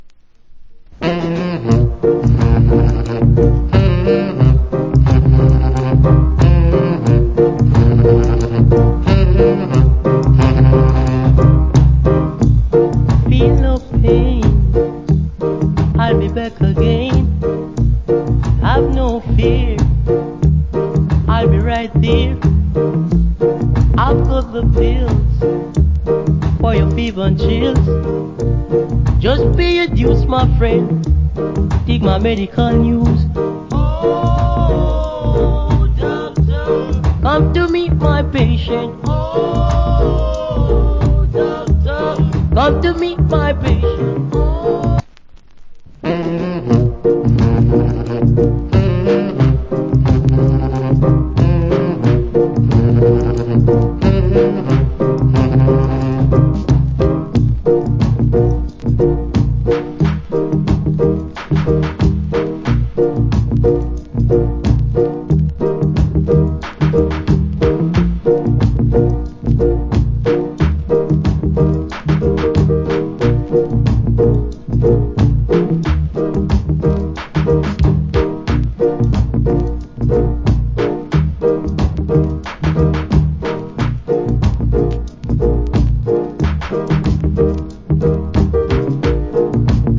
Nice Rock Steady.